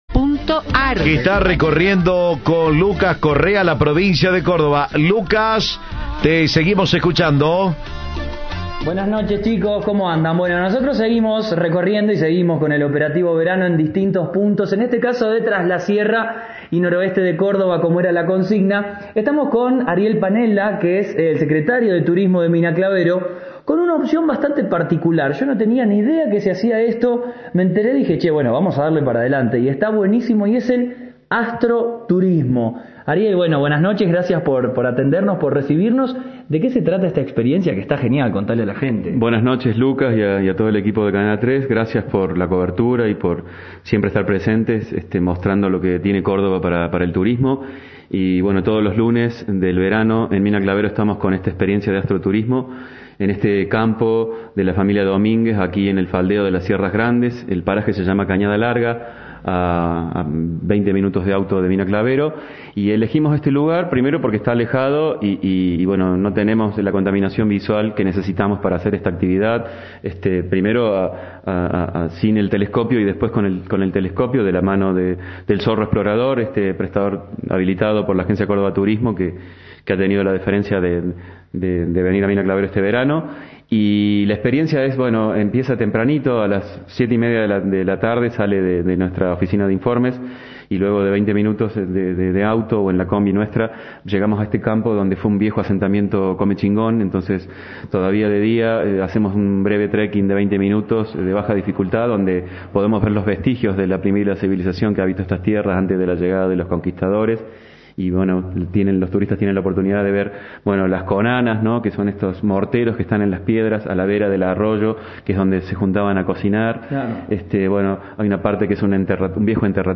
El paseo guiado se realiza los lunes, a 20 minutos en auto de Mina Clavero. El secretario de Turismo de esa ciudad, Ariel Panela, contó en Cadena 3 los puntos claves del imperdible recorrido.